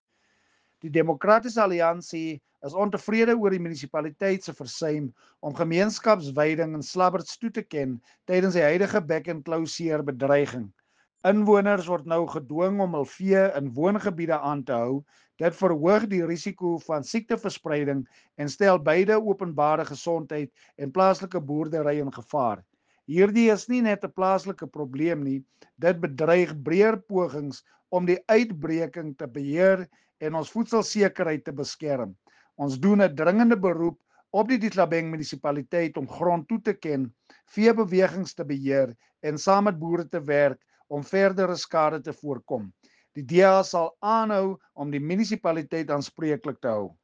Afrikaans soundbite by Cllr Marius Marais.